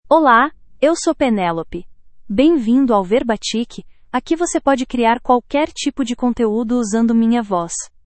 Penelope — Female Portuguese (Brazil) AI Voice | TTS, Voice Cloning & Video | Verbatik AI
Penelope is a female AI voice for Portuguese (Brazil).
Voice sample
Penelope delivers clear pronunciation with authentic Brazil Portuguese intonation, making your content sound professionally produced.